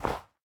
snow3.ogg